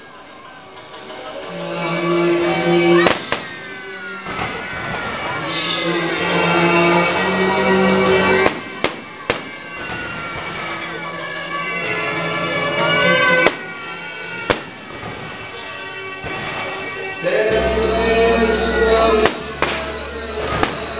feu_artifice_bagniere_bigorre2.wav